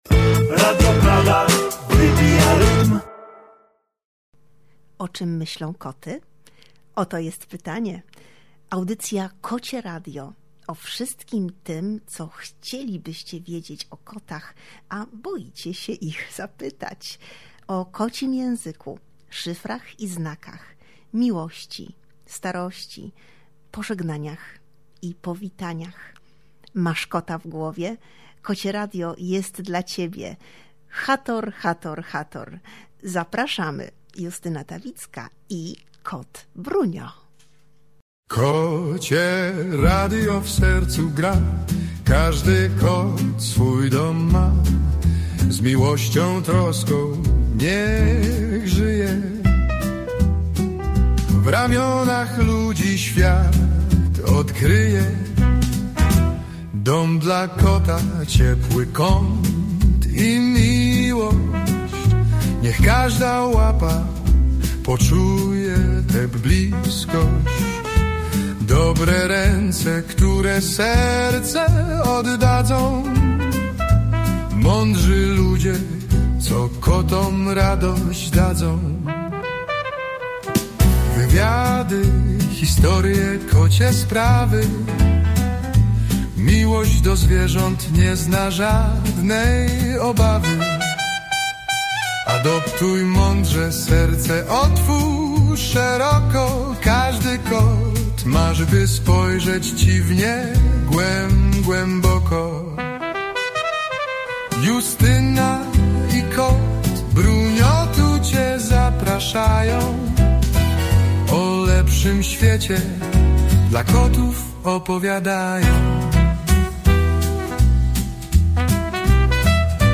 Zapis audio naszej rozmowy znajdziesz tutaj: https